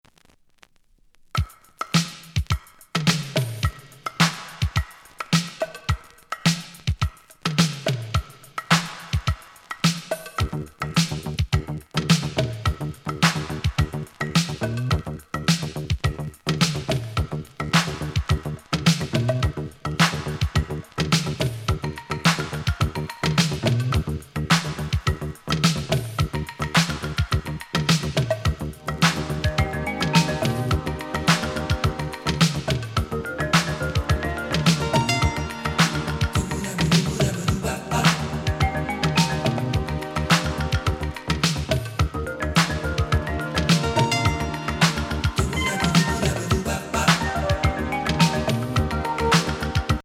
イタリー産ロマンチック＆メロウなバレアリックAORウルトラ名曲！